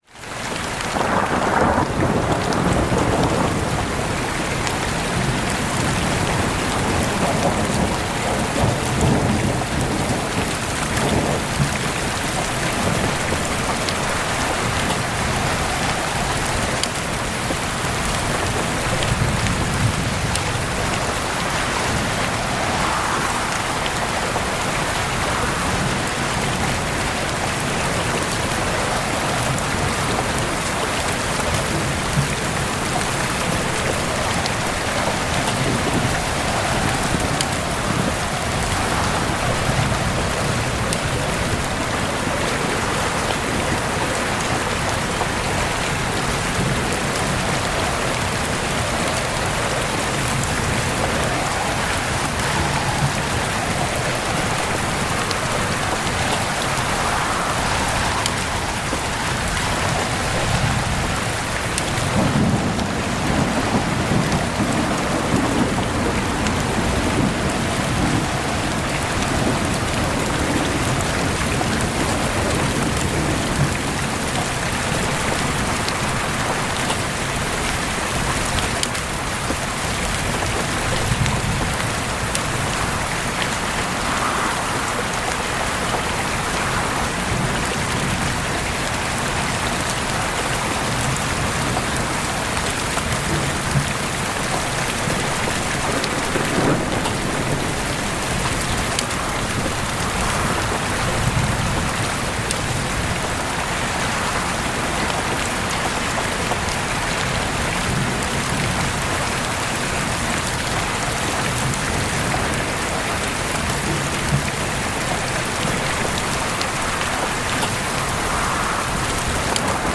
Open Window Rain & Thunder for Cozy Sleep Ambience